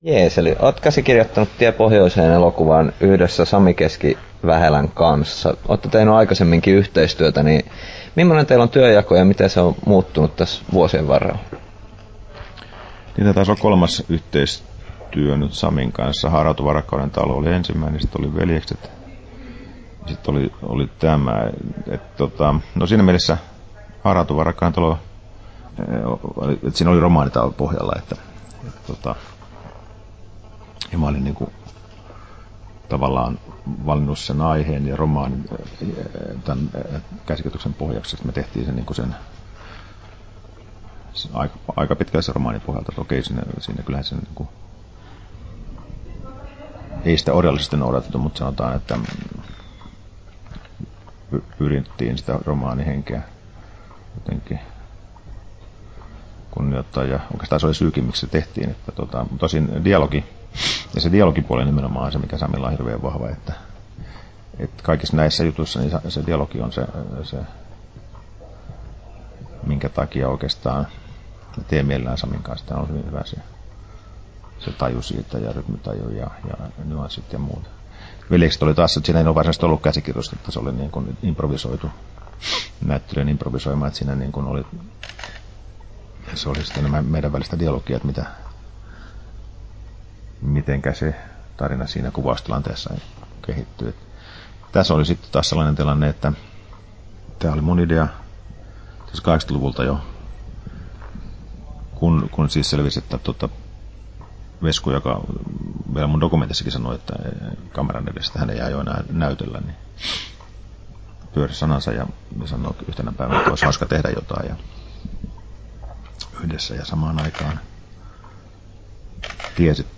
Pressikaravaani on Turussa ja huolimatta lukemattomien haastatteluiden tuomasta kokemuksesta, Mika Kaurismäki kuulostaa pohtivalta. Varsin hiljainen puheääni maistelee ja harkitsee jokaista sanaa.